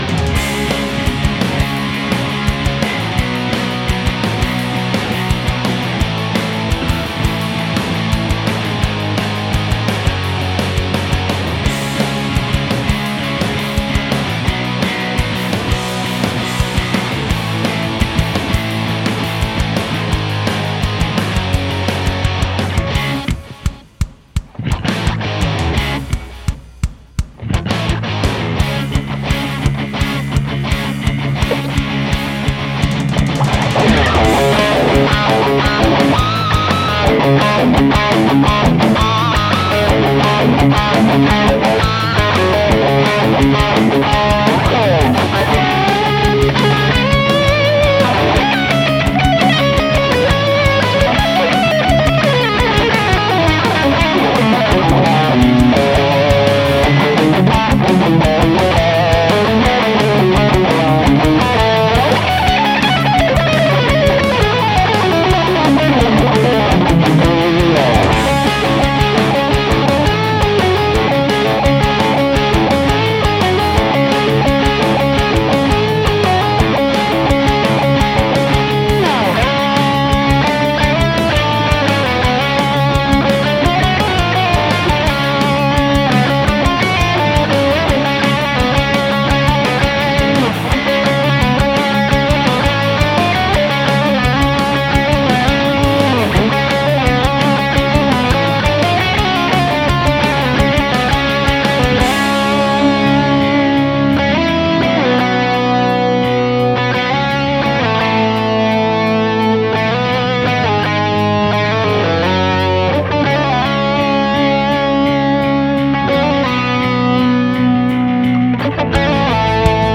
Its a little messy but the idea is there for the impatient solos ...